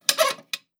padlock_wiggle_06.wav